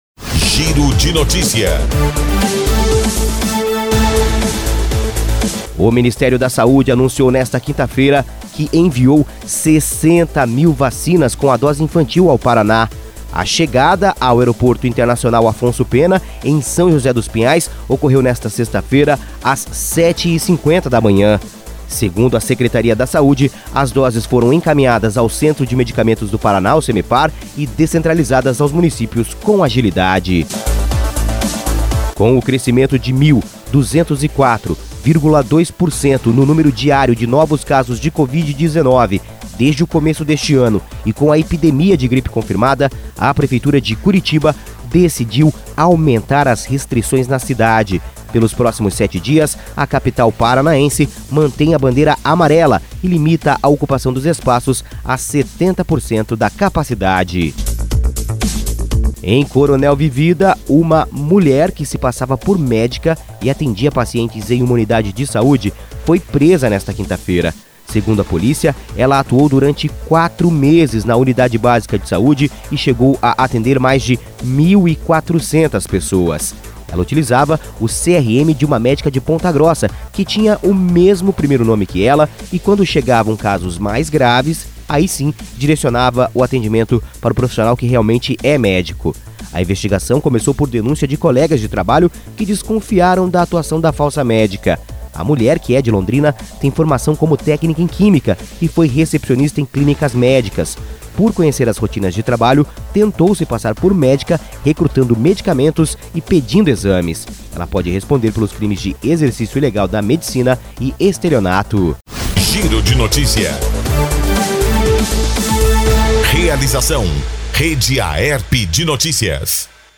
Associação das Emissoras de Radiodifusão do Paraná